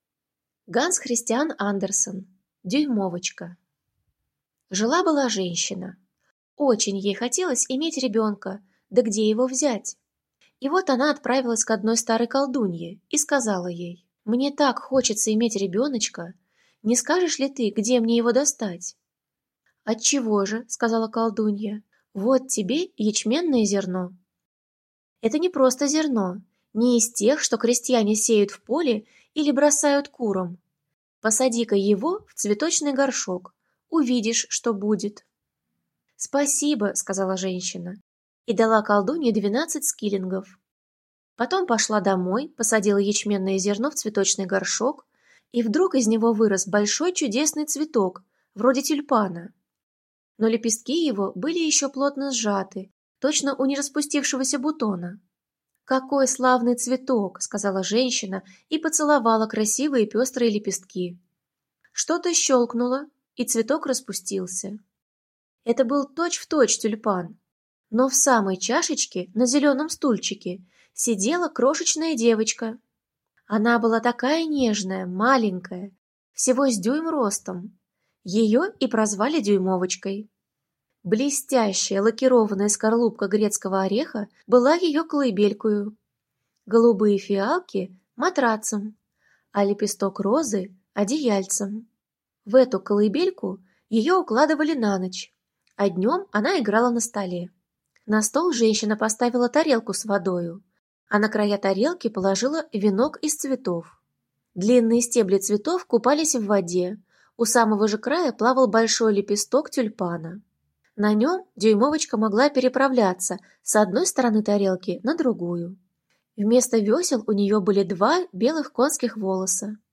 В рамках проекта «Волонтеры читают» мы озвучили эту волшебную историю!